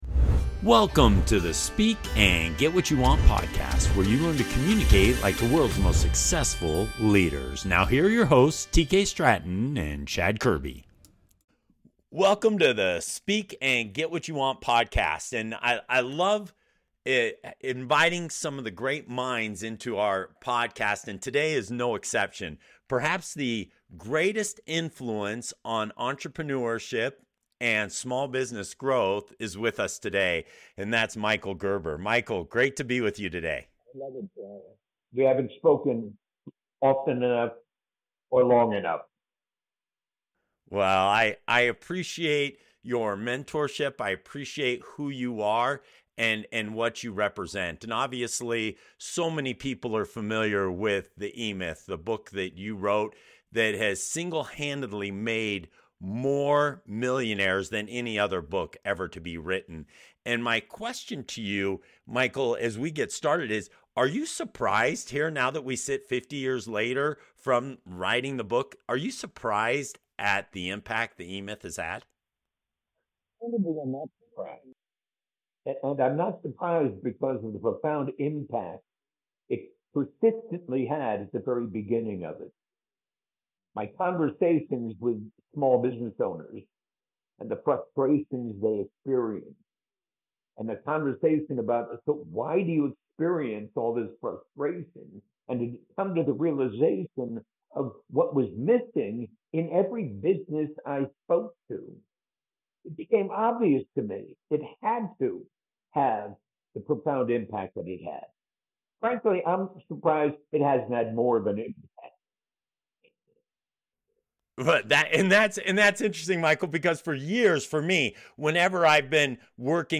Michael Gerber, legendary author of The E-Myth, joins the podcast to share the powerful principles that have transformed countless small businesses. Discover why dreaming big is only the beginning, how a clear vision and a proven process lead to success, and why systems are the key to creating a lasting impact.